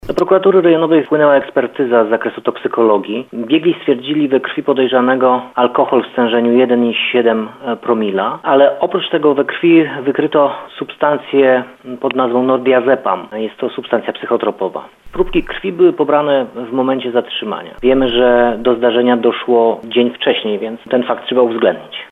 Wiemy, że do zdarzenia doszło dzień wcześniej, więc ten fakt trzeba uwzględnić – mówi prokurator rejonowy w Nowym Sączu Bartosz Gorzula.